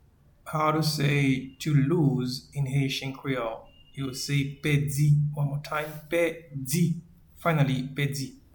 Pronunciation and Transcript:
to-Lose-in-Haitian-Creole-Pedi.mp3